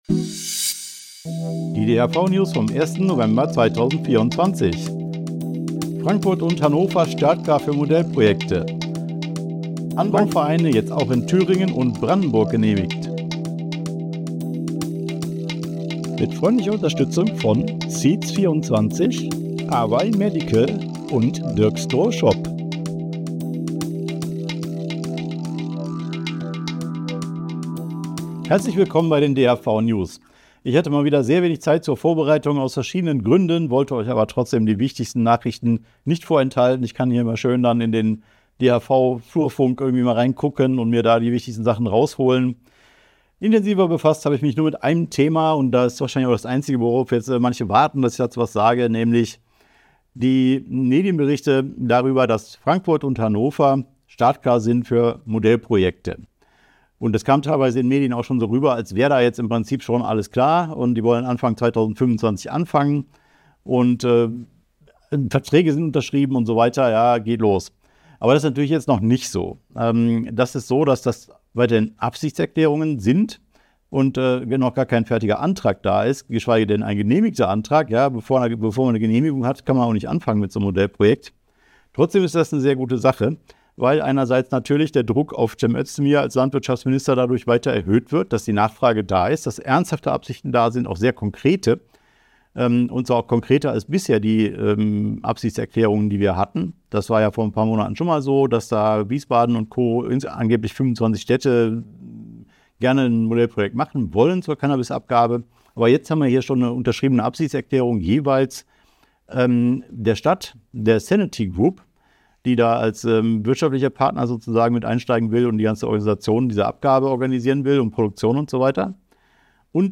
DHV-News # 442 Die Hanfverband-Videonews vom 01.11.2024 Die Tonspur der Sendung steht als Audio-Podcast am Ende dieser Nachricht zum downloaden oder direkt hören zur Verfügung.